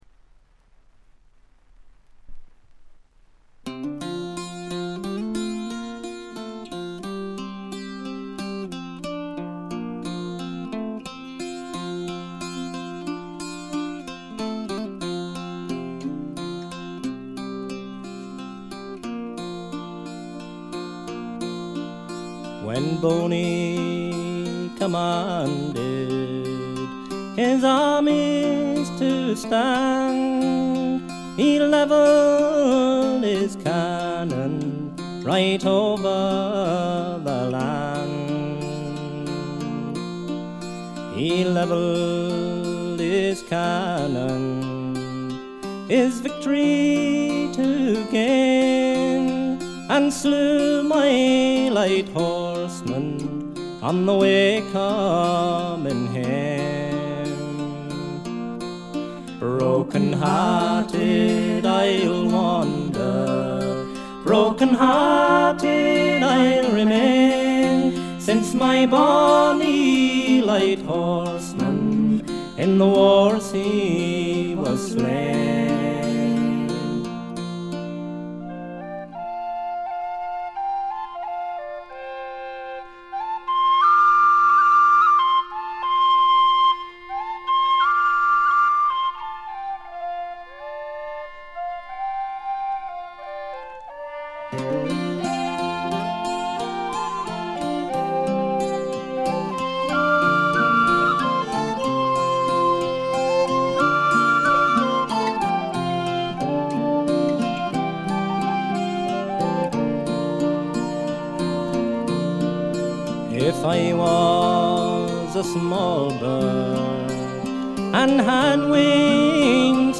ほとんどノイズ感無し。
声自体が重層的で深いんですよね。
試聴曲は現品からの取り込み音源です。